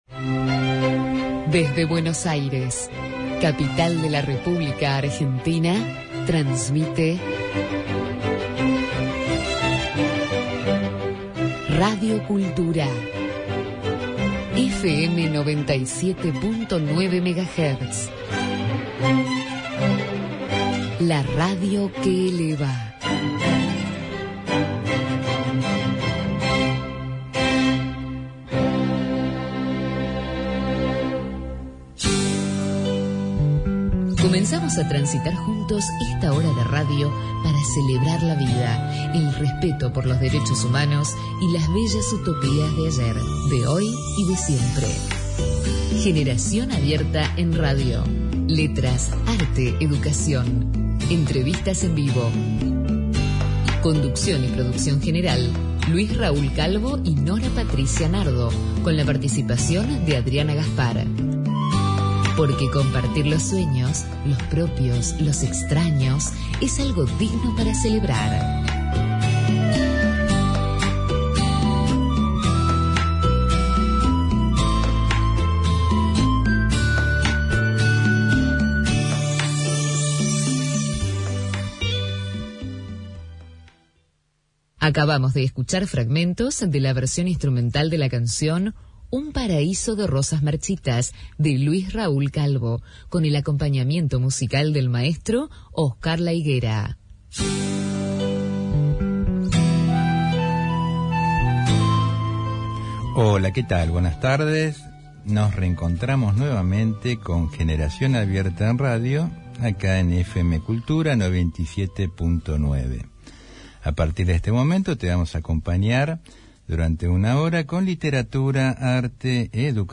– Últimos debates en temas educativos. Por la Radio AM 1010 “Onda Latina” , Buenos Aires, Argentina.